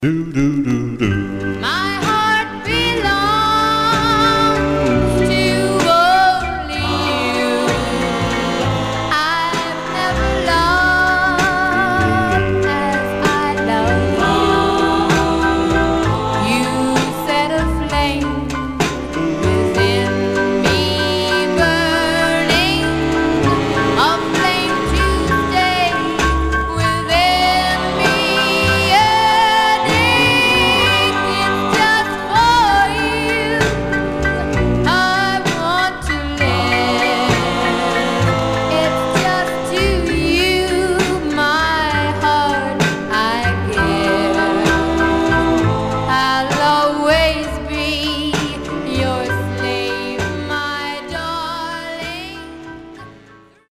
Condition Some surface noise/wear Stereo/mono Mono
Teen